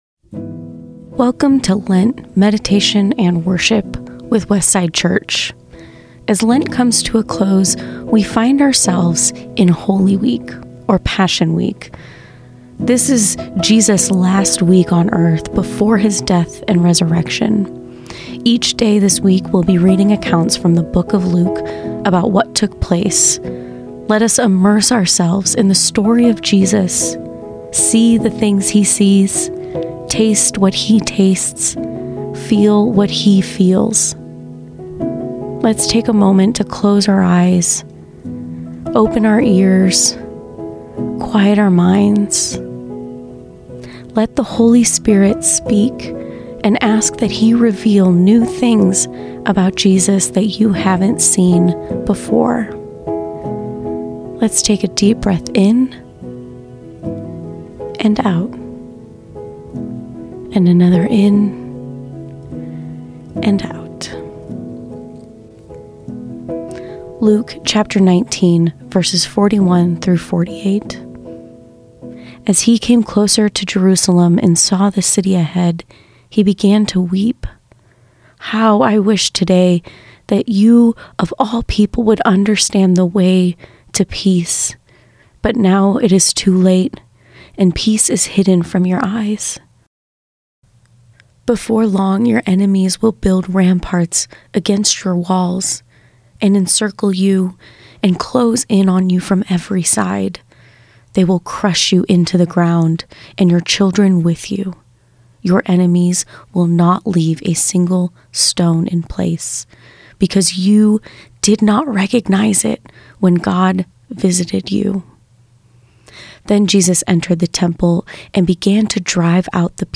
A reading from Luke 19:41-48